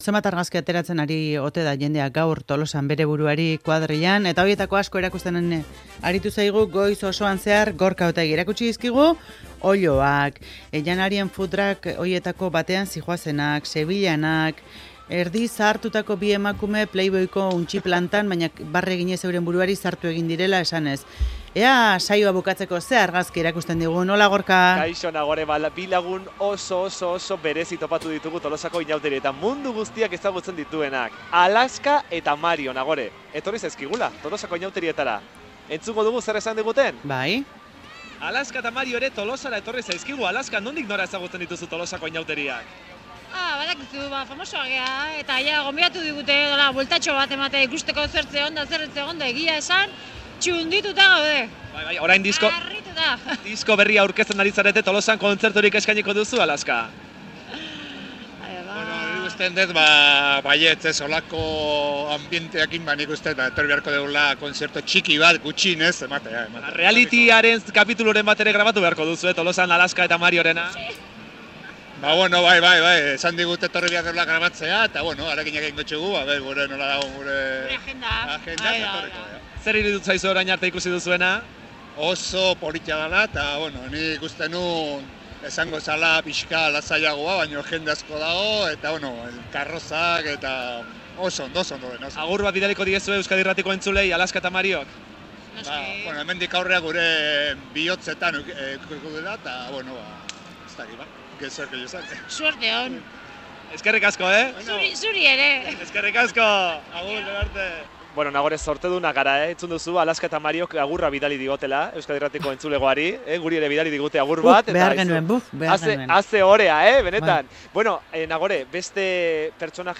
Zaldunita eguneko inauteri-kronika egin digu Tolosatik